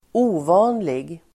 Uttal: [²'o:va:nlig]